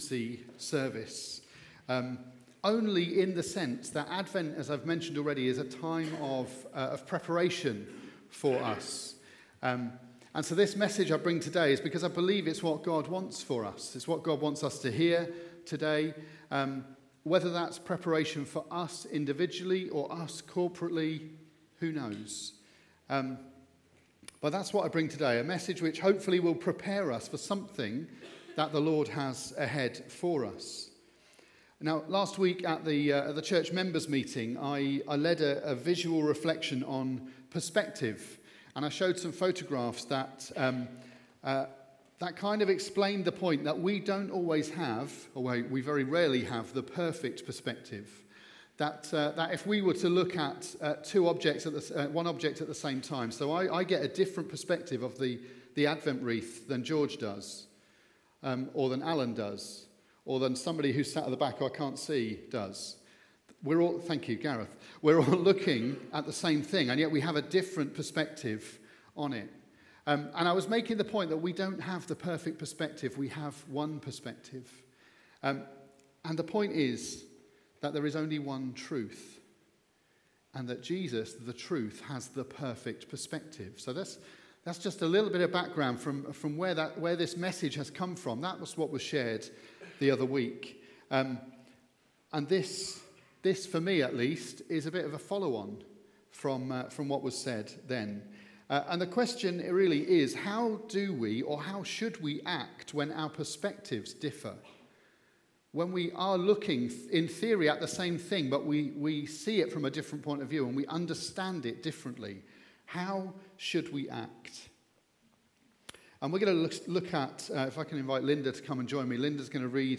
A message from the series "Other."